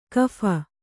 ♪ kapha